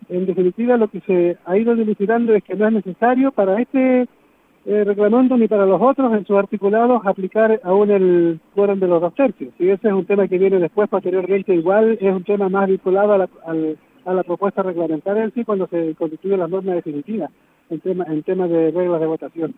Consultado por Radio Bío Bío, Victorino Antilef, quien fue patrocinado por comunidades de Los Ríos, dijo que ese quórum debe ser aplicado cuando esté el reglamento definitivo y no cuando se debatan sus propuestas.